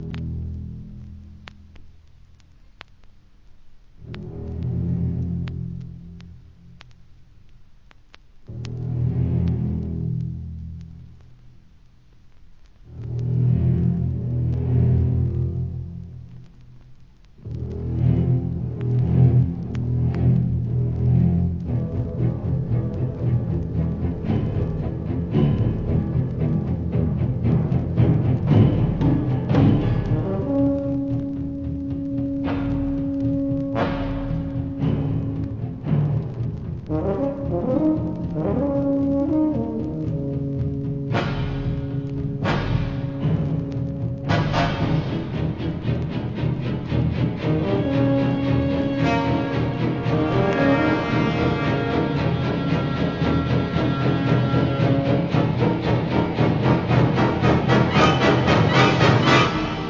恐怖感を煽る効果音として余りにも有名!!